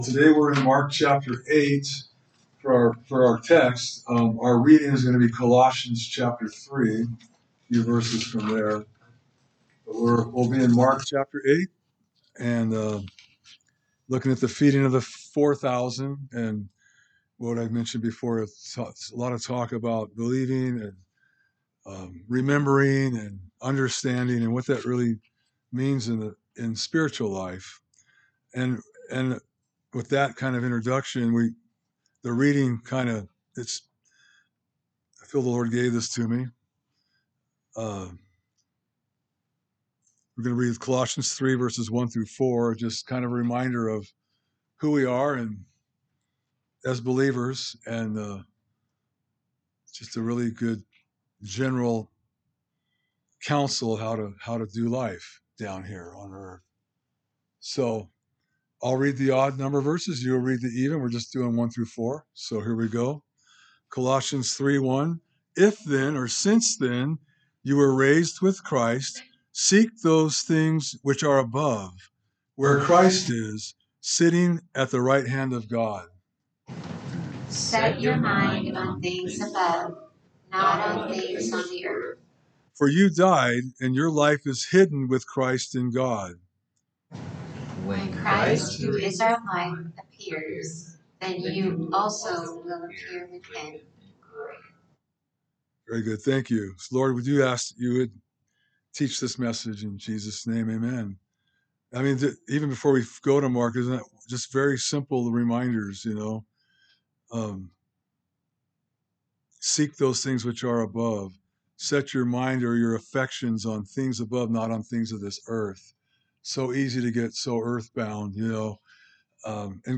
A message from the series "Mark."